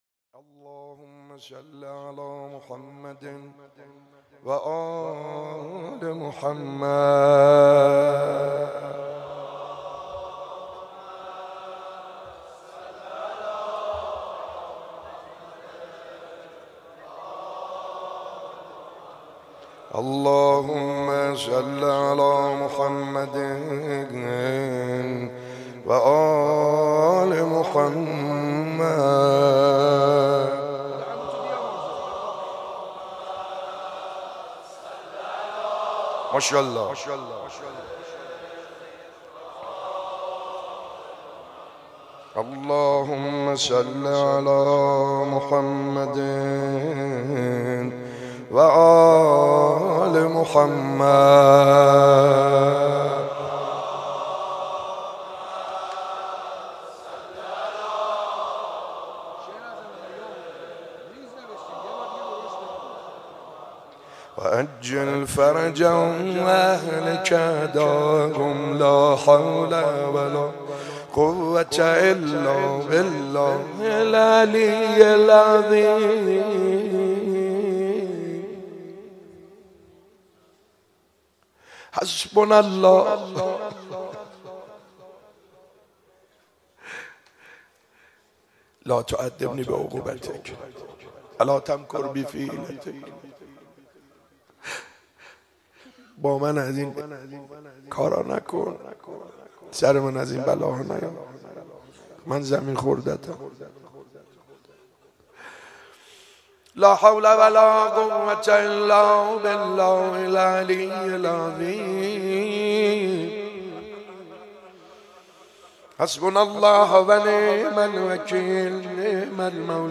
شب سوم ماه رمضان 95_مناجات خوانی